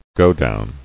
[go·down]